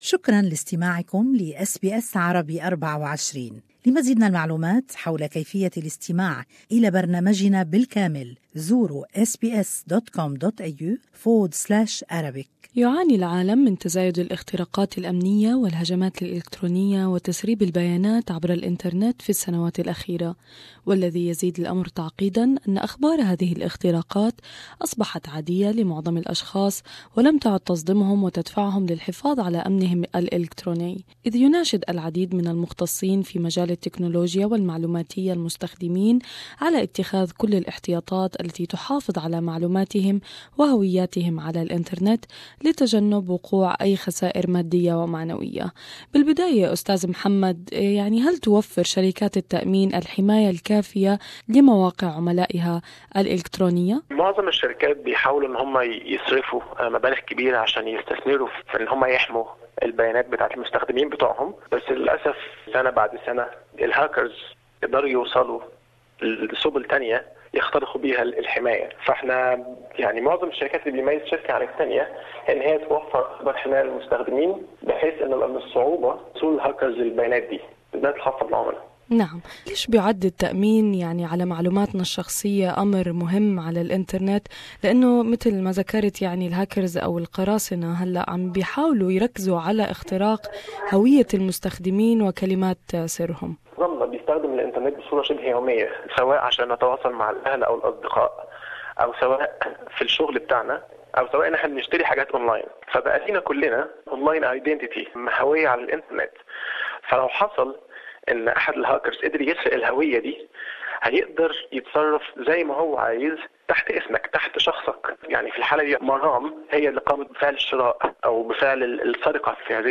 interviewed Network and IT Engineer